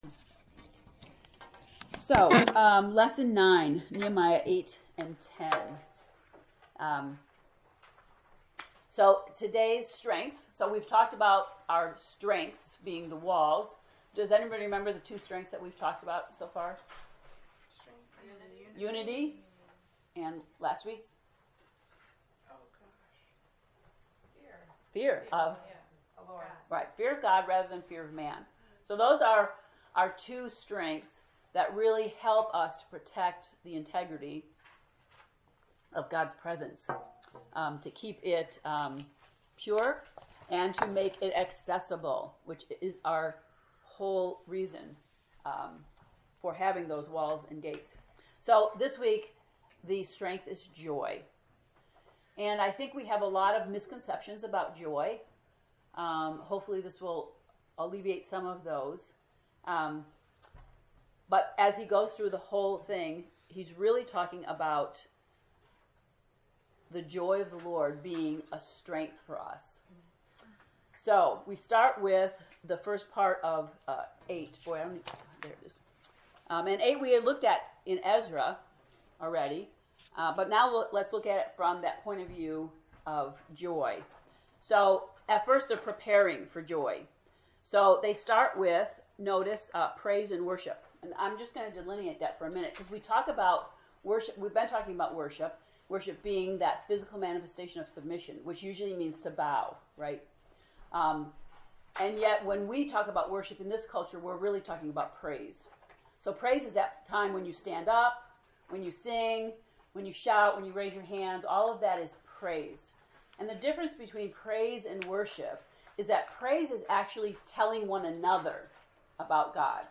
To listen to the lesson 9 lecture, “The strength of joy,” click below: